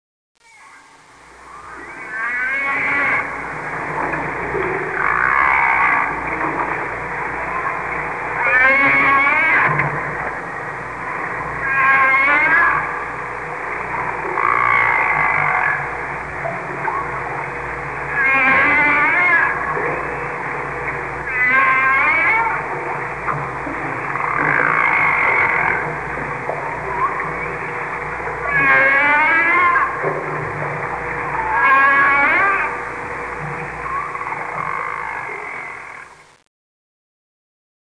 whale1.mp3